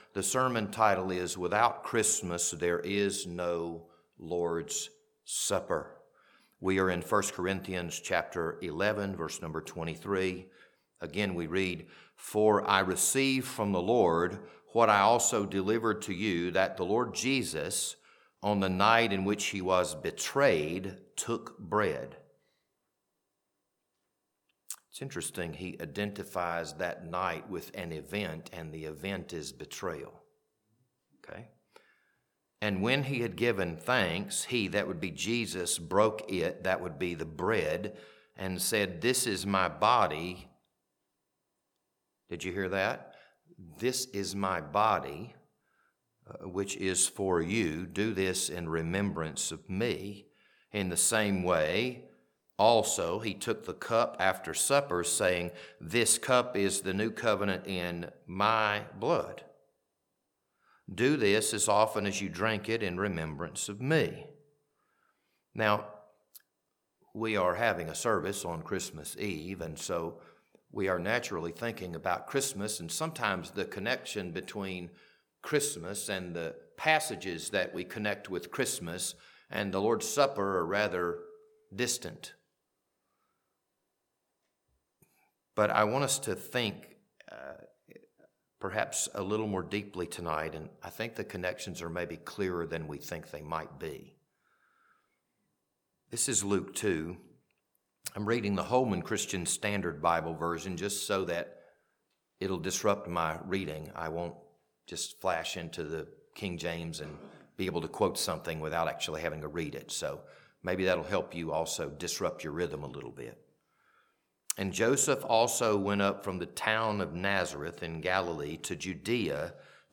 This Christmas Eve sermon was recorded on December 24th, 2025.